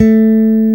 Index of /90_sSampleCDs/Roland LCDP02 Guitar and Bass/BS _E.Bass v_s/BS _5str v_s